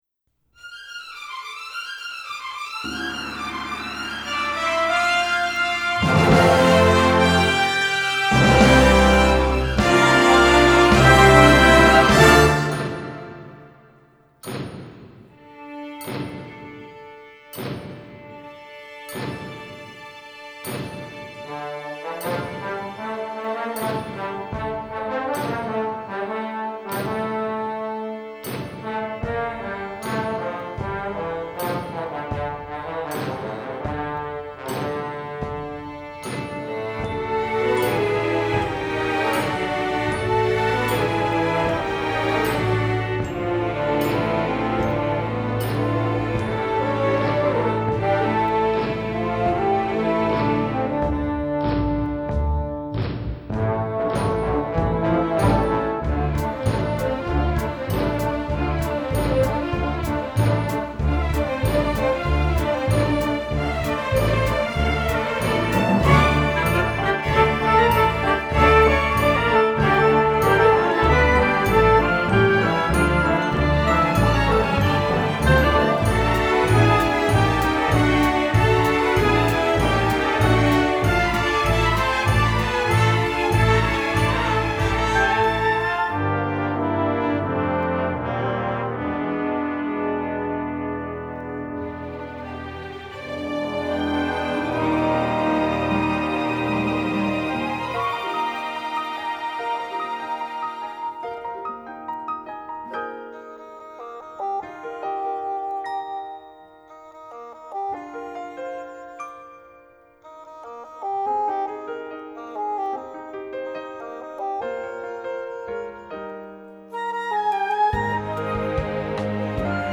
Voicing: Full Orchestra